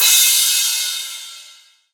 Crashes & Cymbals
pbs - heavy [ Crash ].wav